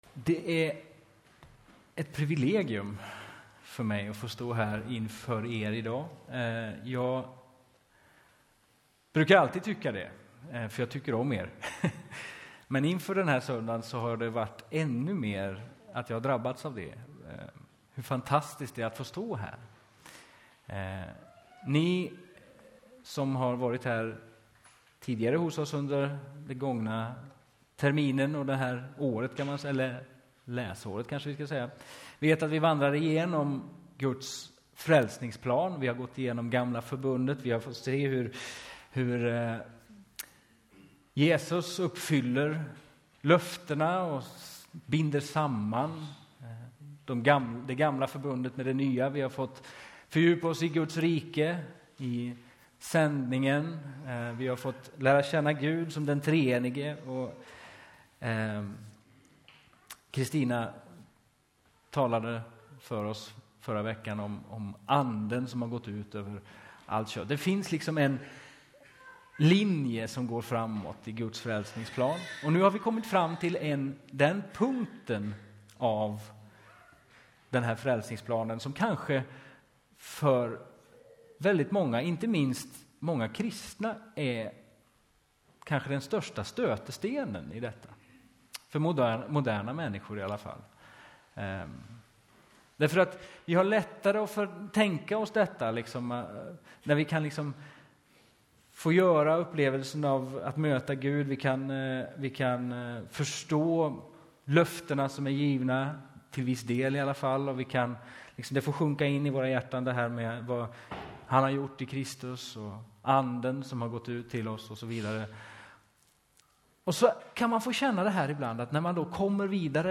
Predikoserie: Genom NT (våren 2018) Etiketterad med Apostlagärningarna , Församlingen